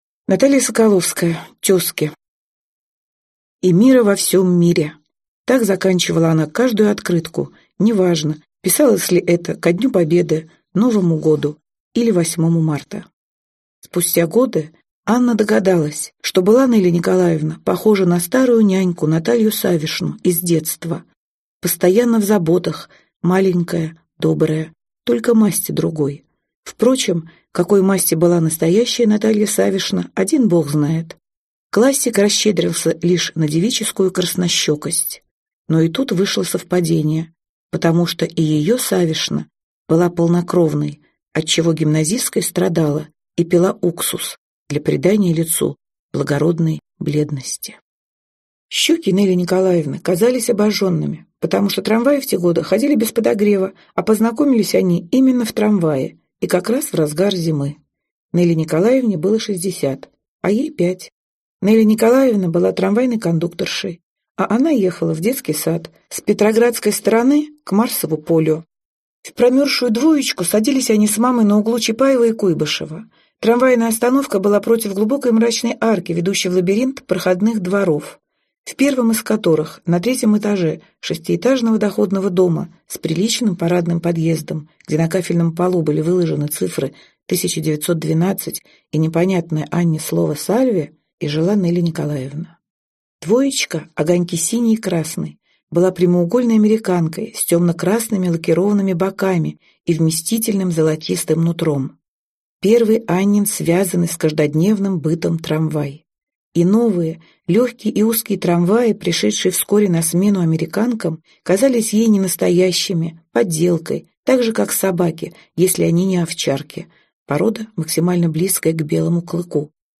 Аудиокнига Тёзки. рассказ | Библиотека аудиокниг